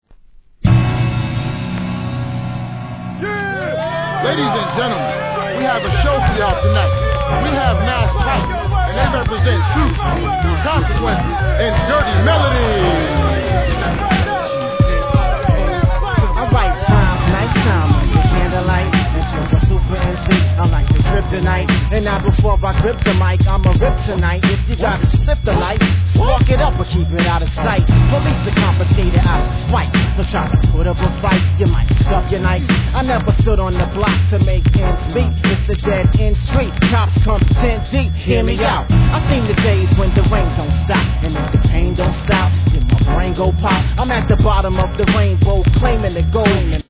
HIP HOP/R&B
シンプルながら煙たくもFATなプロダクション、たまりません!!